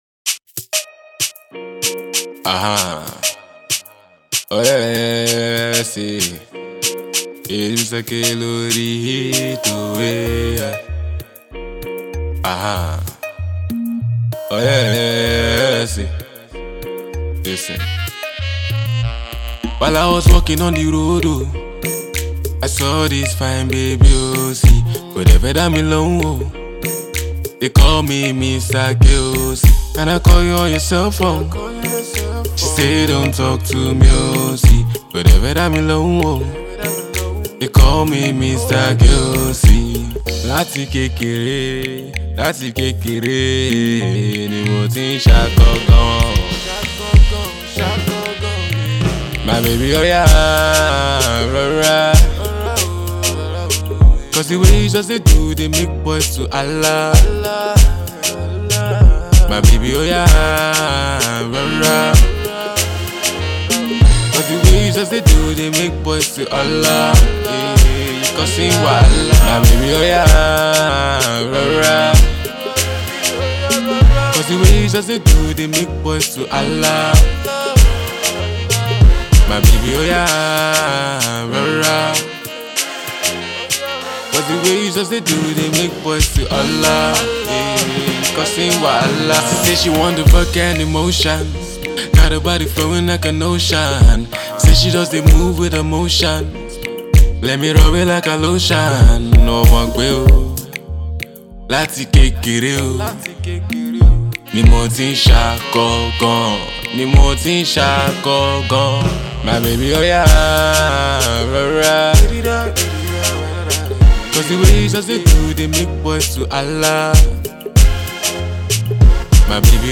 Indie artist
husky-sounding underground act
Over a mid-tempo soundscape filled with horn riff